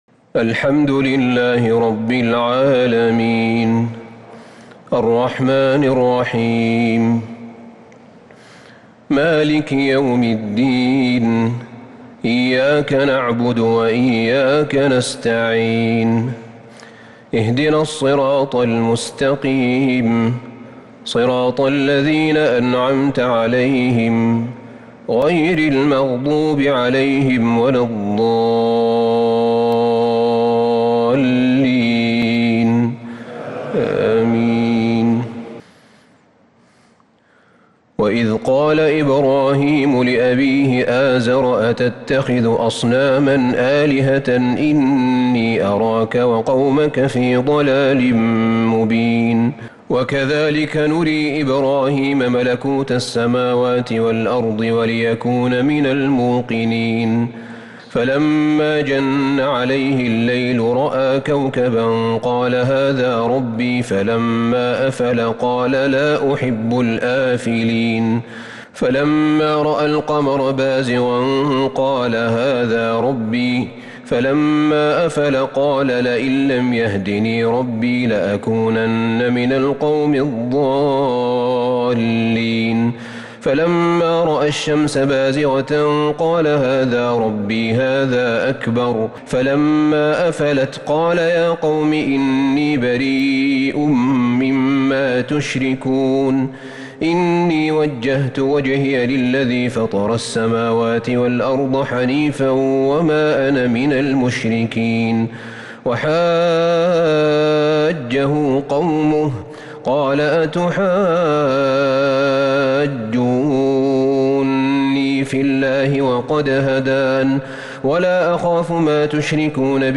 تراويح ليلة 10 رمضان 1443هـ سورة الأنعام (74-135) |taraweeh 10st niqht ramadan Surah Al-Anaam1443H > تراويح الحرم النبوي عام 1443 🕌 > التراويح - تلاوات الحرمين